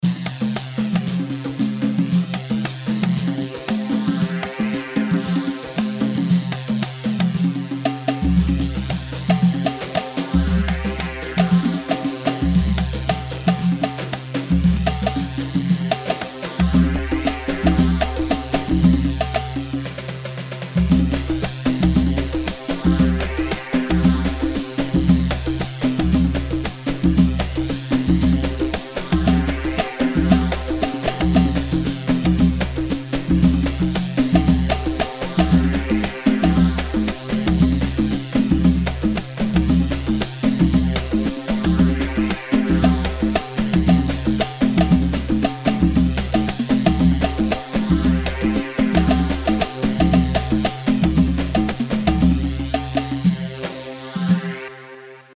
Lewa 2/4 115 ليوا
Loop